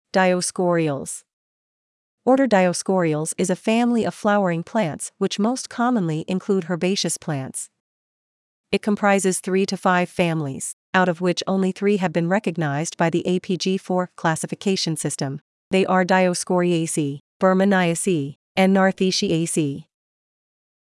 Dioscoreales Pronunciation
Dioscoreales-pronunciation.mp3